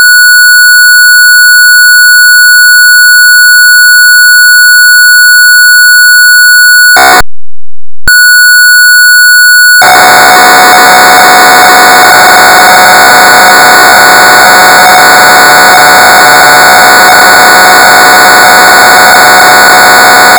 ПК-01 Львов tape loader